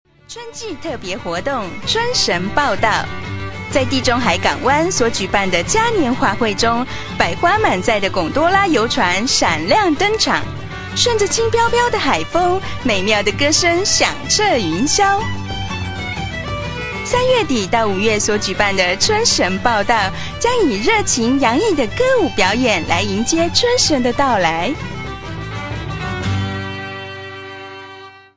台湾北京語ナレーター（繁体字圏）をご紹介いたします。